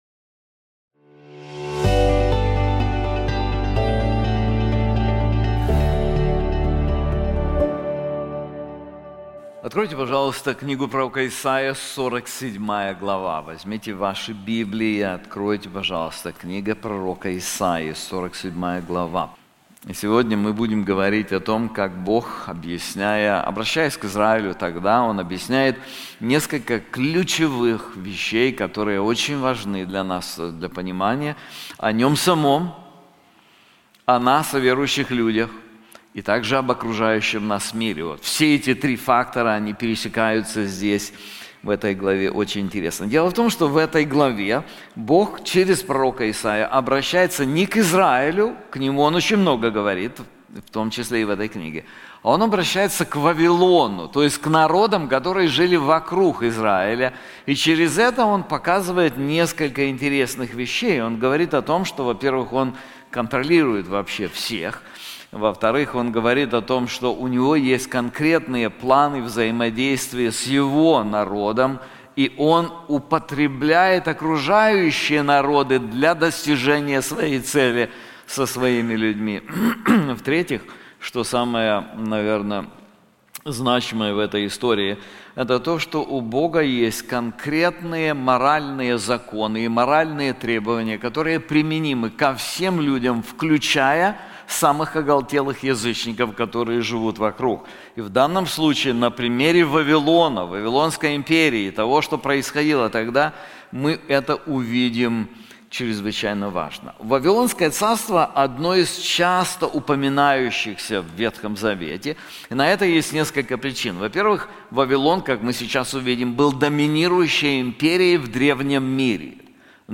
This sermon is also available in English:The Catastrophe of Self-Confidence • Isaiah 47:1-15